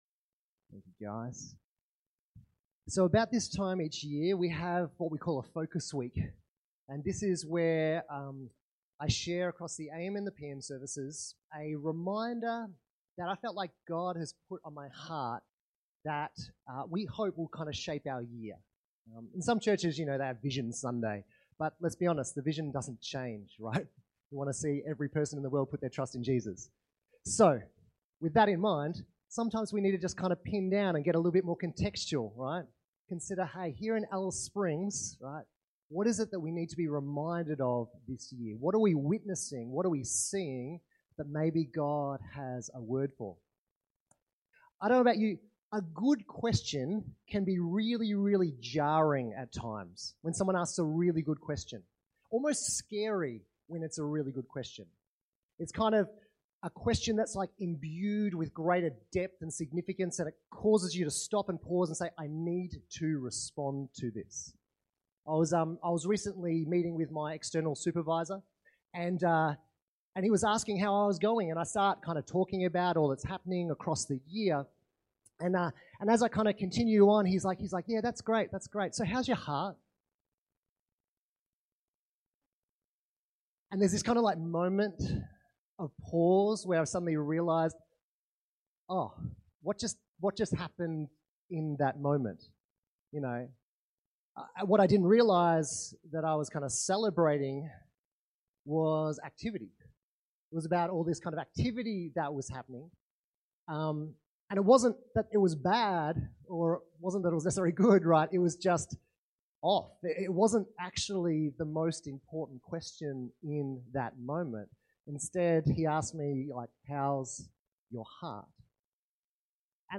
Sermons – Alice Springs Baptist Church